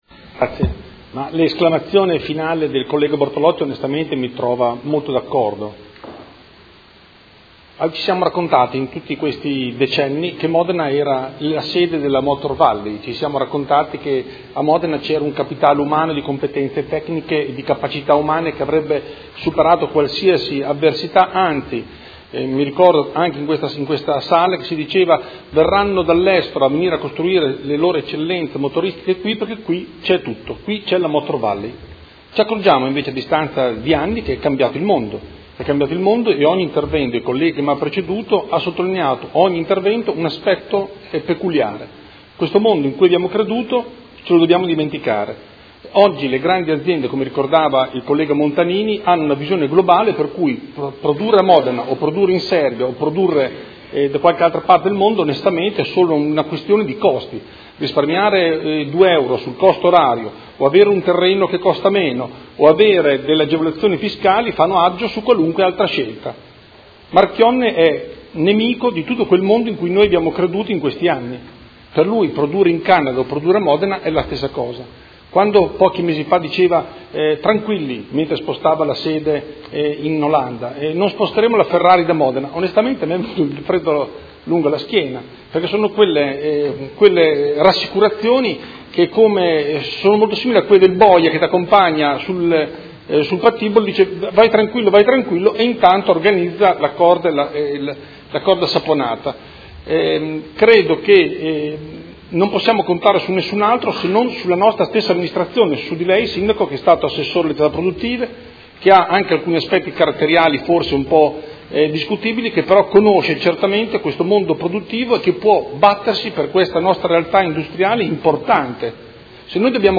Andrea Galli — Sito Audio Consiglio Comunale
Seduta del 20/04/2016. Dibattito su interrogazione dei Consiglieri Malferrari, Trande e Bortolamasi (P.D.) avente per oggetto: Si aggrava la crisi alla Maserati di Modena